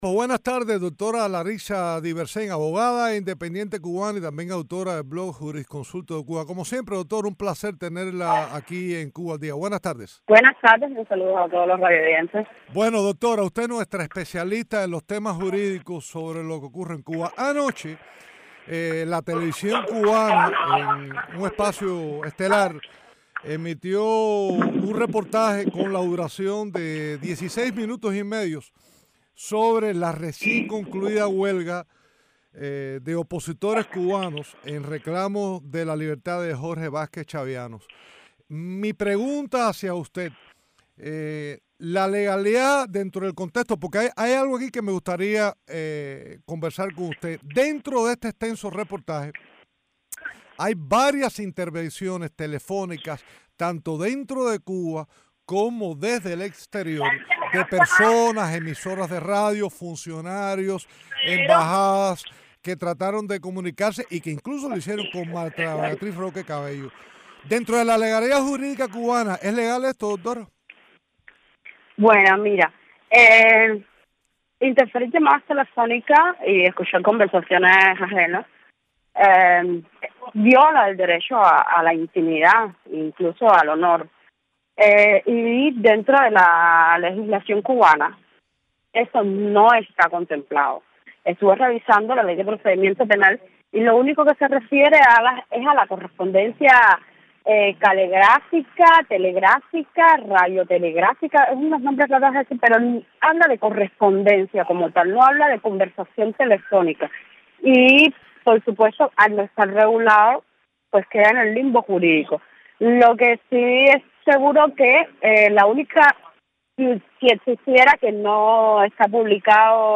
programa radial Cuba al día